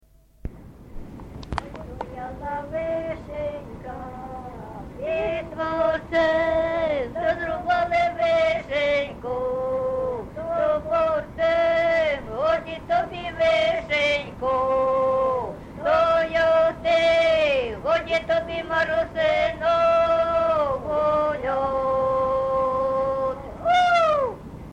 ЖанрВесільні
Місце записус. Харківці, Миргородський (Лохвицький) район, Полтавська обл., Україна, Полтавщина